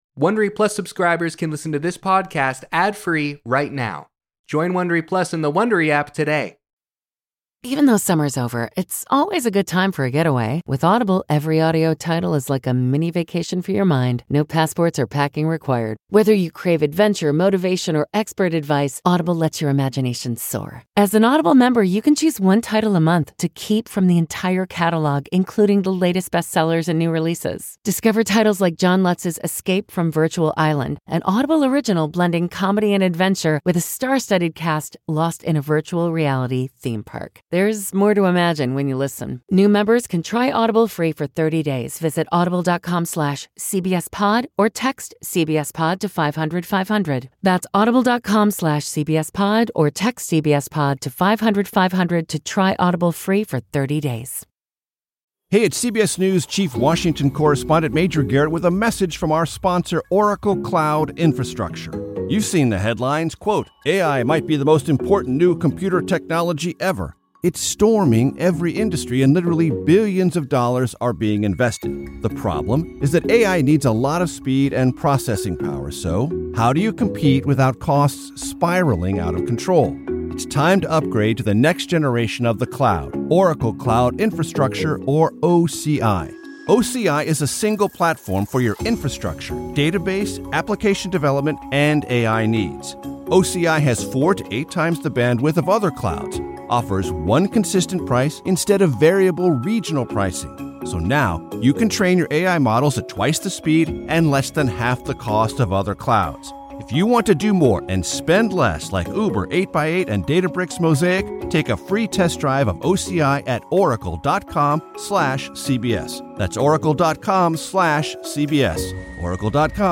Hosted by Jane Pauley. In our cover story, Robert Costa talks with election officials about threats to your right to vote.